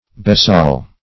Besaiel \Be*saiel"\, Besaile \Be*saile"\, Besayle \Be*sayle"\,